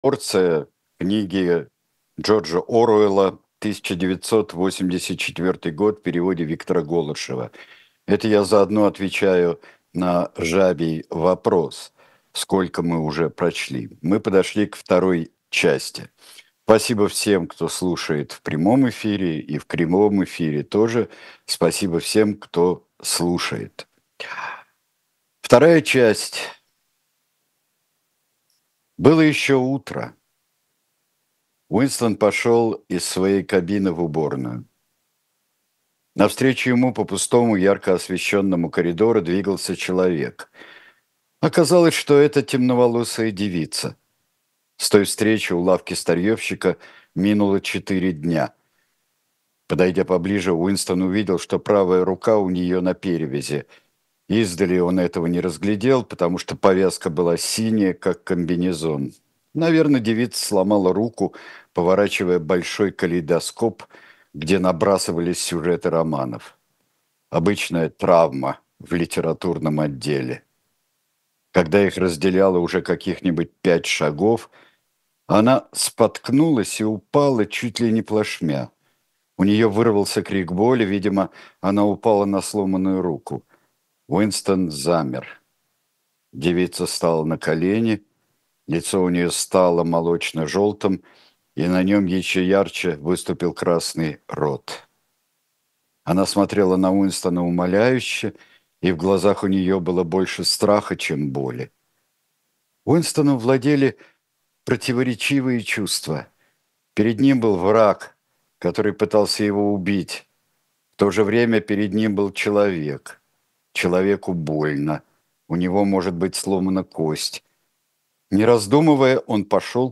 Сергей Бунтман читает роман Джорджа Оруэлла
1984-dzhordzha-oruella-chast-6.-chitaet-sergej-buntman.mp3